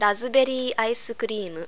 razuberi:aisukurimu'.